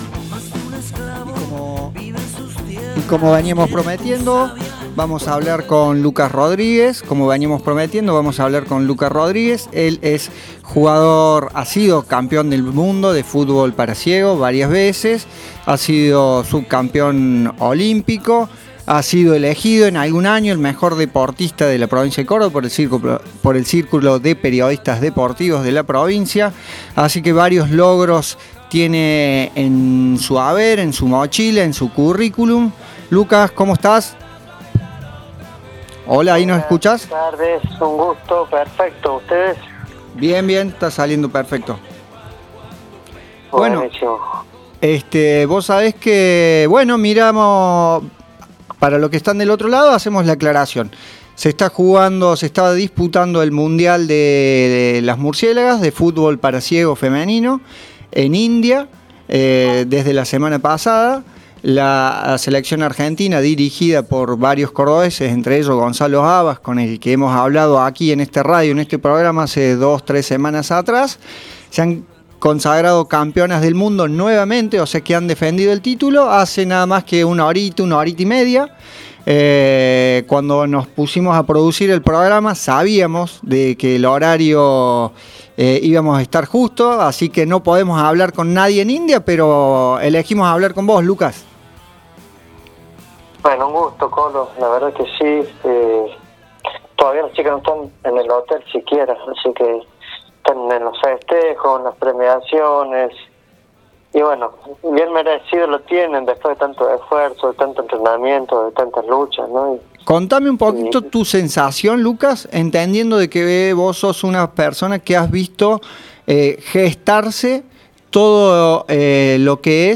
En diálogo con Conexión Sport Sierras Chicas